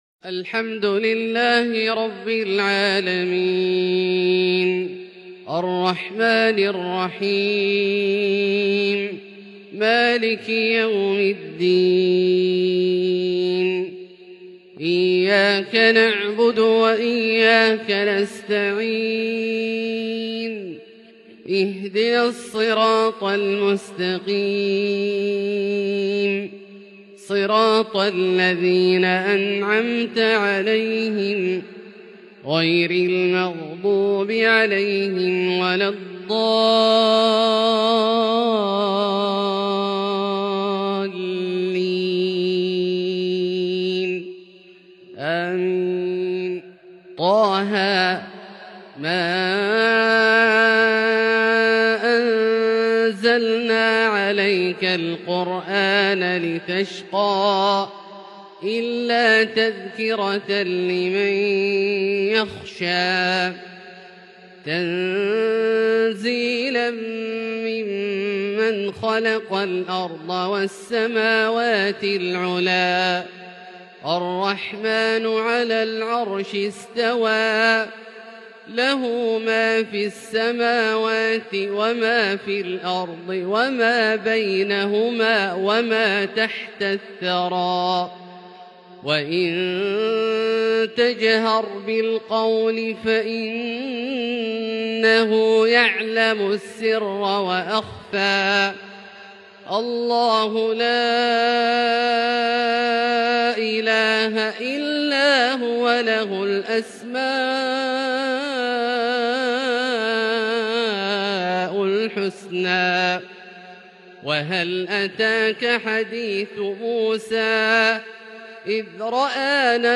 فجر الثلاثاء 6-6-1442 هــ من سورة طه | Fajr prayer from Surat Taa-Haa 19/1/2021 > 1442 🕋 > الفروض - تلاوات الحرمين